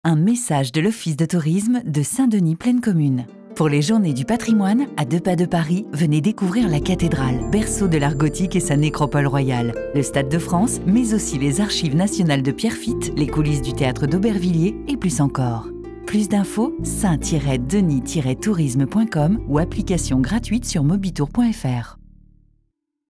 Spot publicitaire de l'Office de Tourisme de Saint Denis Plaine Commune
pub_office_tourisme_saint_denis_2013.mp3